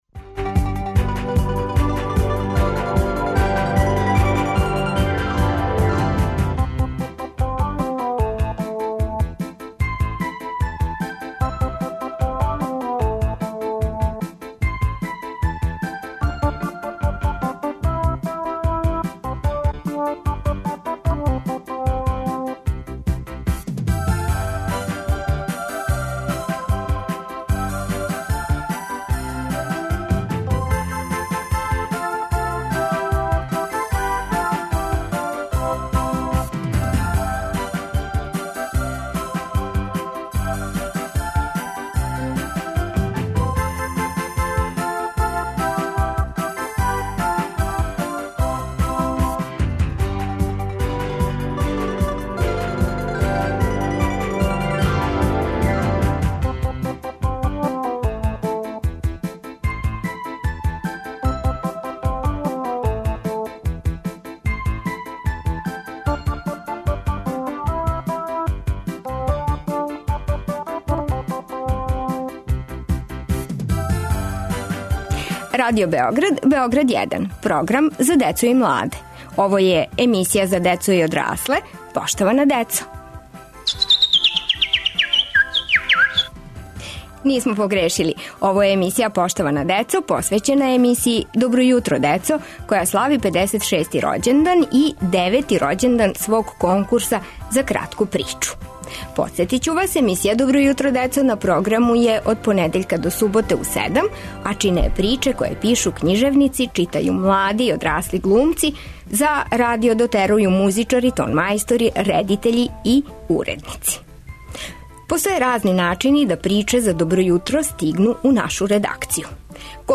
Како се будимо, какве приче волимо и како их писци стварају за децу? Гости су нам деца и писци прича за добро јутро.